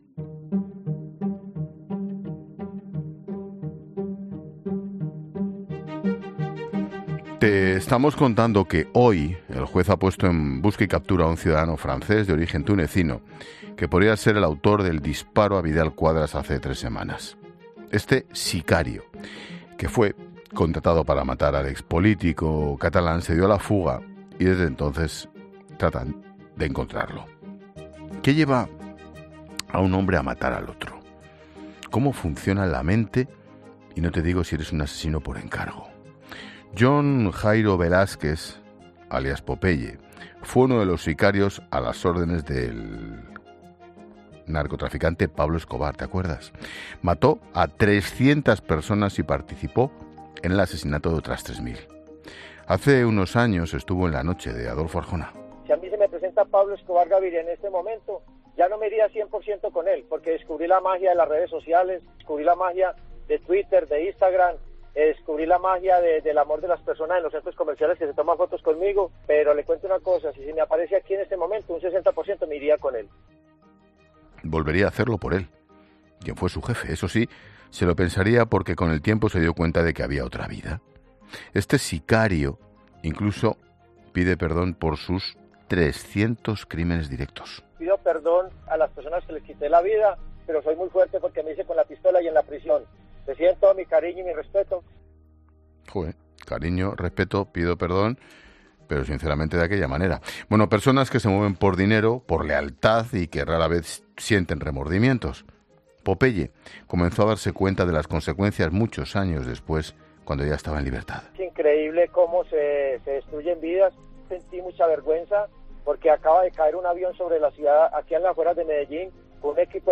Caso Vidal Quadras: una psicóloga explica cómo funciona la mente de un sicario